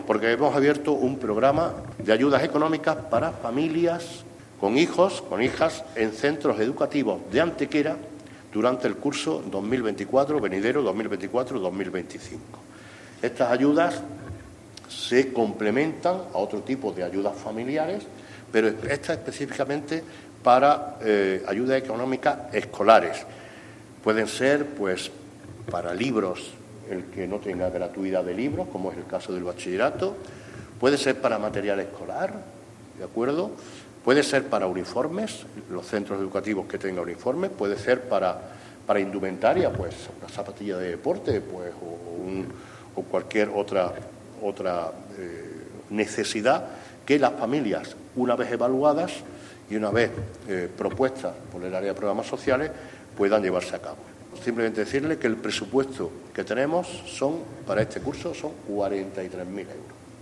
El alcalde de Antequera, Manolo Barón, el teniente de alcalde delegado de Bienestar Social, Alberto Arana, y la concejal de Infancia y Familia, María Sierras, han presentado en rueda de prensa el nuevo programa municipal de ayudas económicas para familias con hijos en centros educativos durante el curso 2024-2025.
Cortes de voz